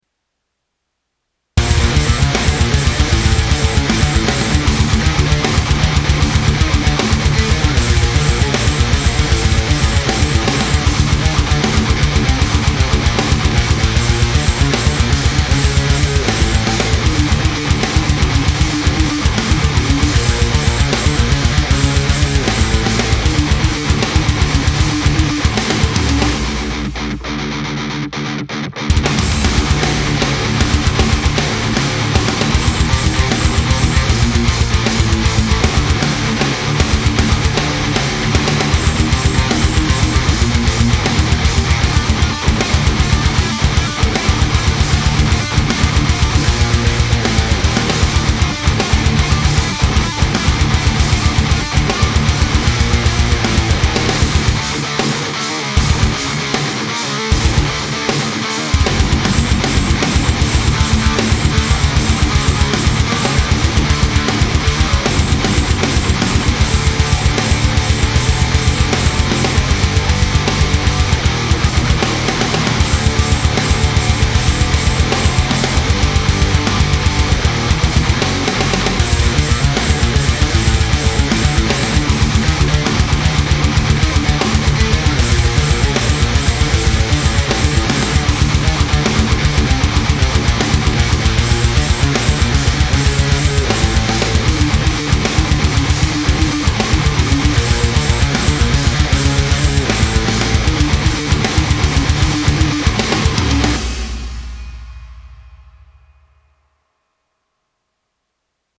bass is a tad too loud :) Yes, yes it is.